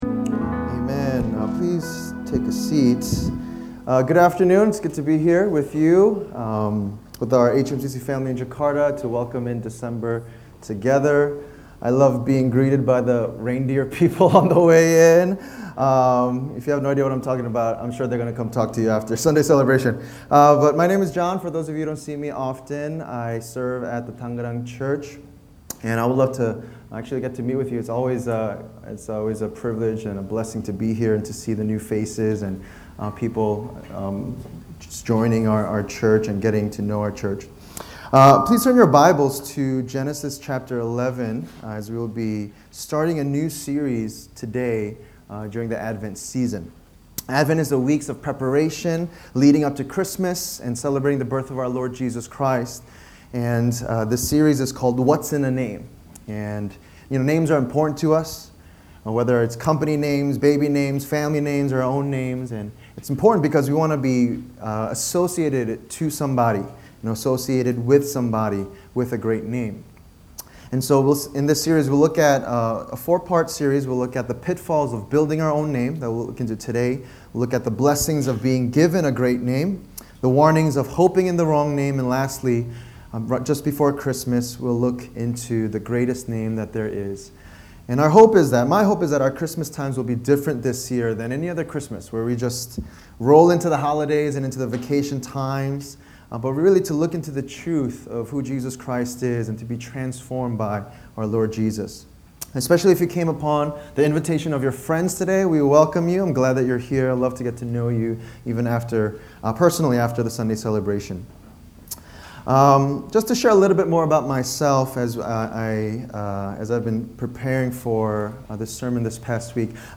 Sermon Summary